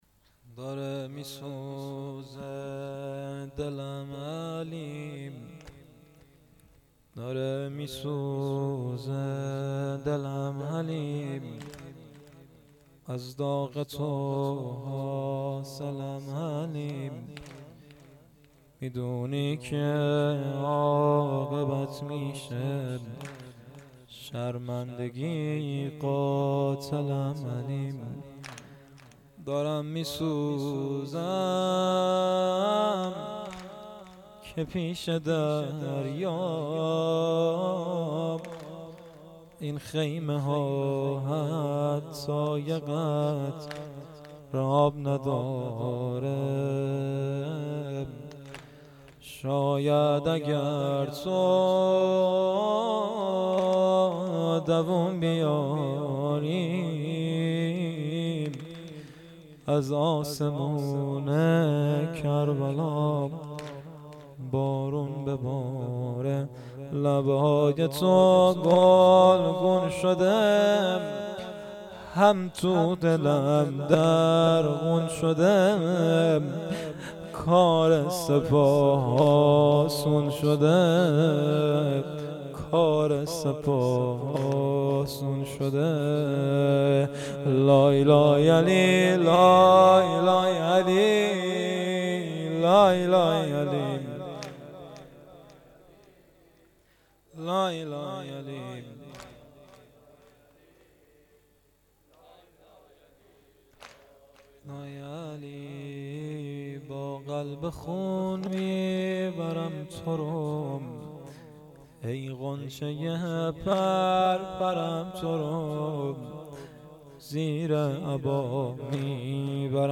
مراسم عزاداری دهه اول محرم الحرام 1399 - مسجد صاحب الزمان (عج) هرمزآباد